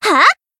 BA_V_Yukari_Battle_Shout_2.ogg